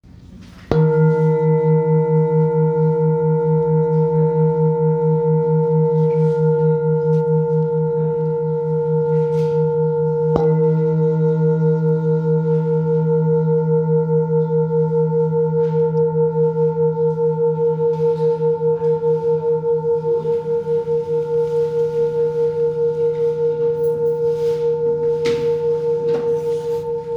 Singing Bowl, Buddhist Hand Beaten, with Fine Etching Carving, Select Accessories
Weight 1.86 kg - 4.1 lbs
Material Seven Bronze Metal